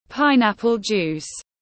Nước dứa ép tiếng anh gọi là pineapple juice, phiên âm tiếng anh đọc là /ˈpaɪnˌæp.əl ˌdʒuːs/
Pineapple juice /ˈpaɪnˌæp.əl ˌdʒuːs/